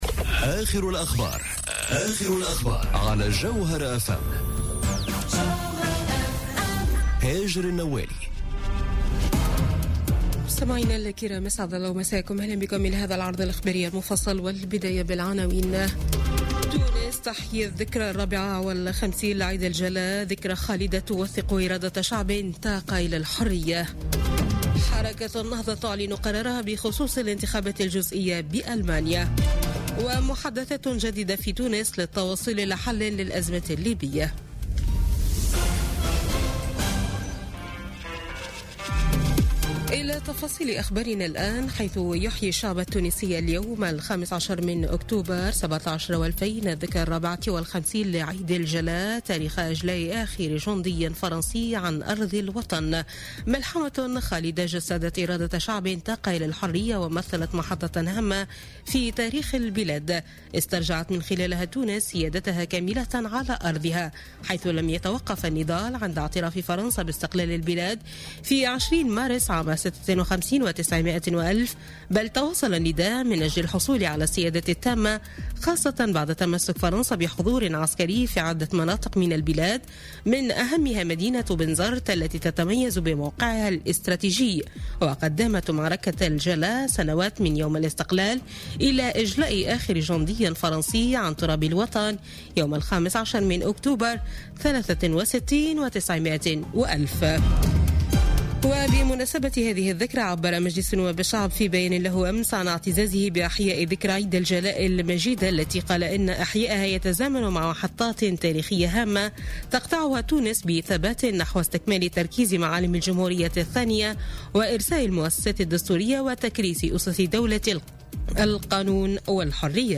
نشرة أخبار منتصف الليل ليوم الأحد 15 أكتوبر 2017